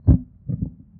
【効果音】紙がボッと燃える - ポケットサウンド - フリー効果音素材・BGMダウンロード
fireup.mp3